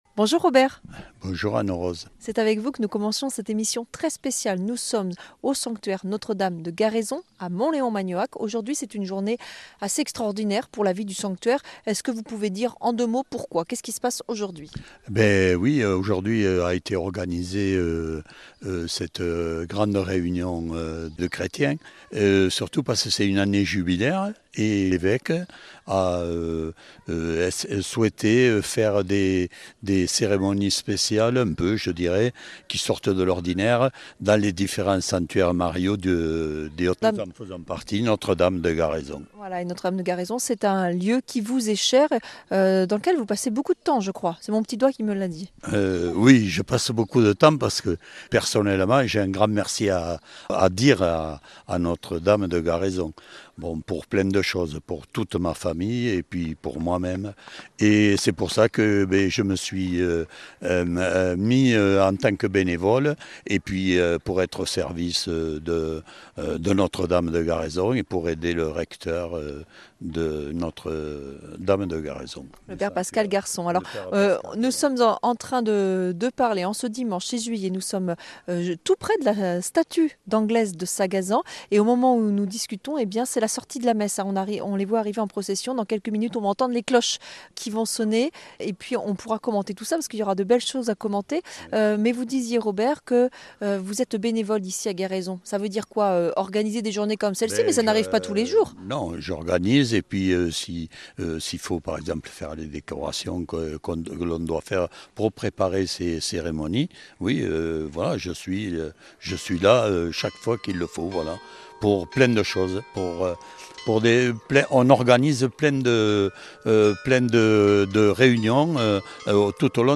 Au milieu des champs, 4000 pèlerins du monde entier viennent chaque année à Garaison. Découvrez l’histoire de ce lieu, et les témoignages de ces pèlerins aux messages portés par la foi.